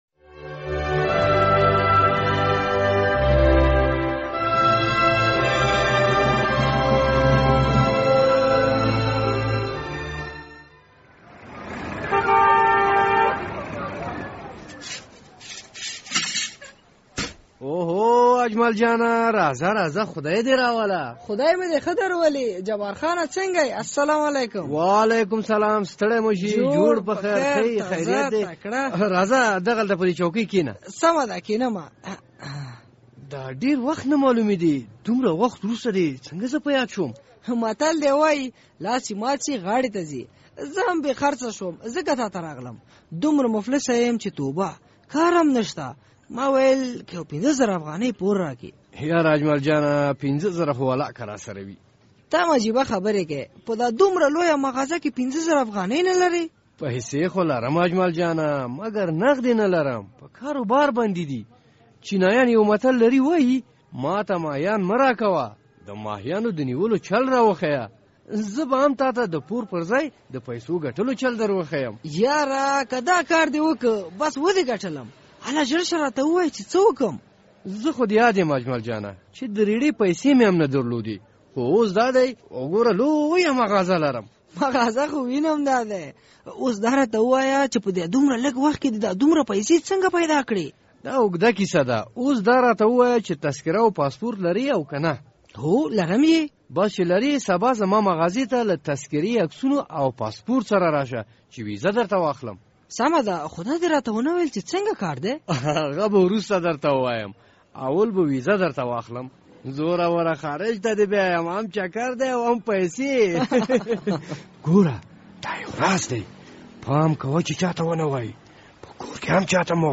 د زهرو کاروان پروګرام ډرامه